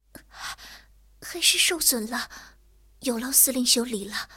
T34-85小破修理语音.OGG